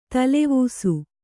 ♪ talevūsu